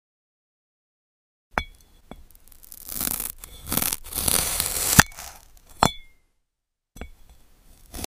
do follow for more ASMR sound effects free download